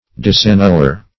Disannuller \Dis`an*nul"ler\, n. One who disannuls.